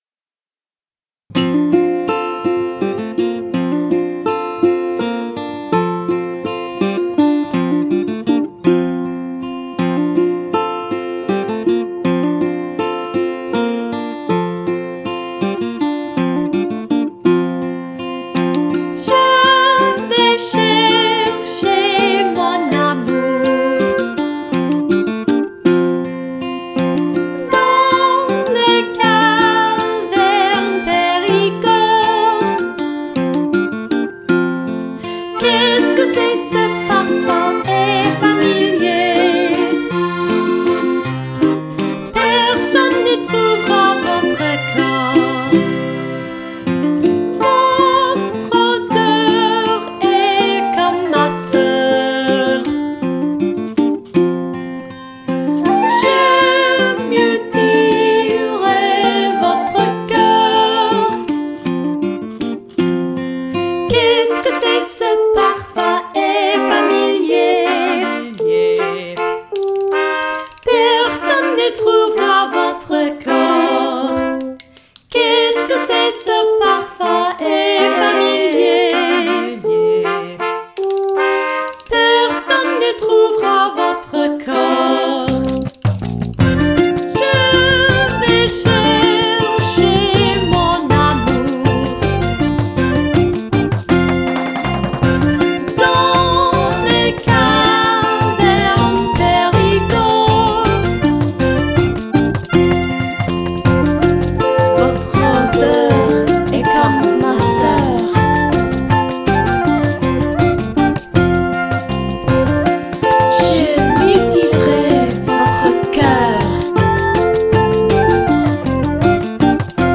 vocals
flute
french horn, trumpet, saxophone